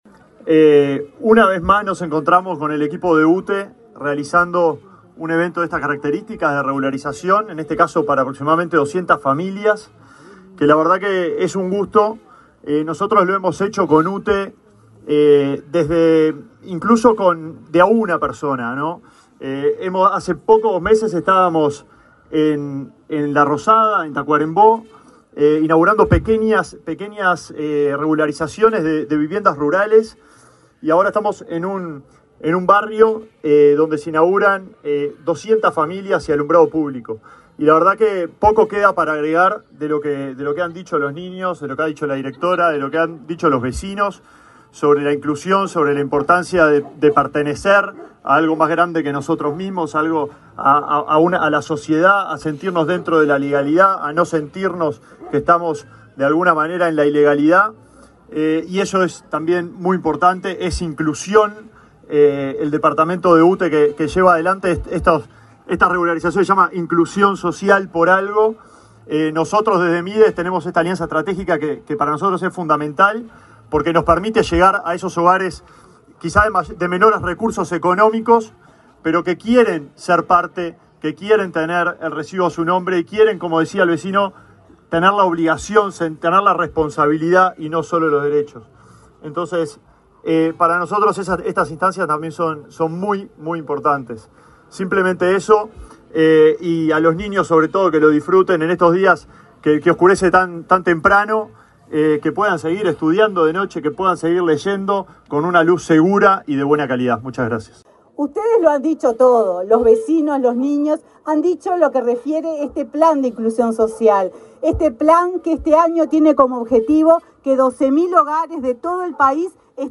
Palabras de autoridades en inauguración de UTE en Canelones